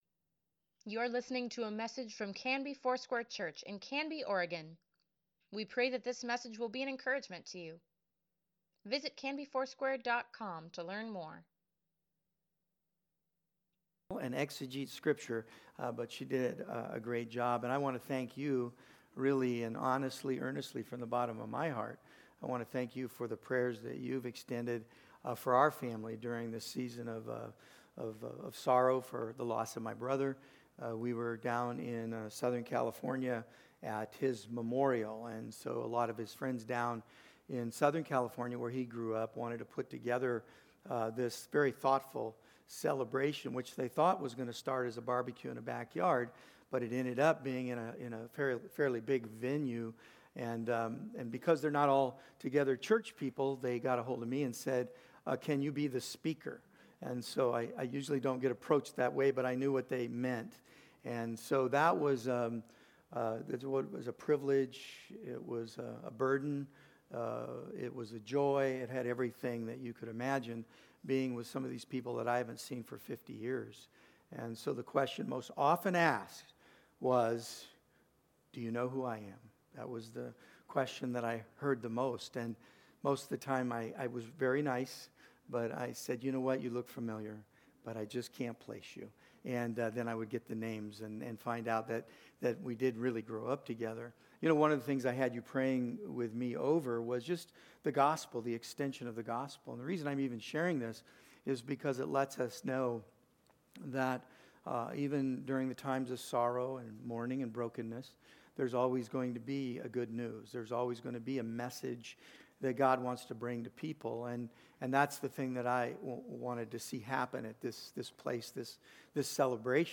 Sunday Sermon | May 5, 2024